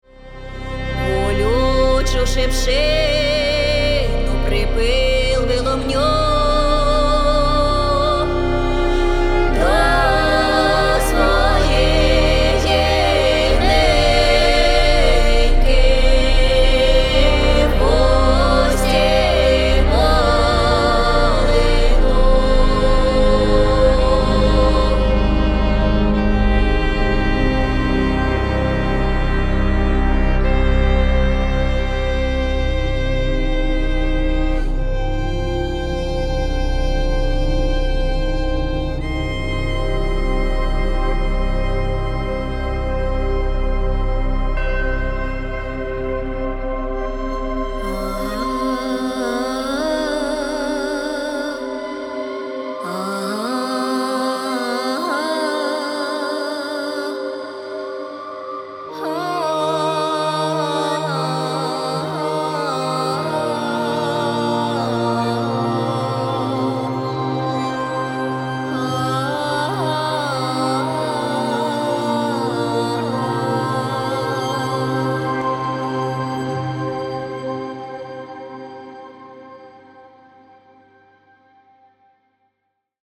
electric violin, violin and viola
and church bells
Score Excerpt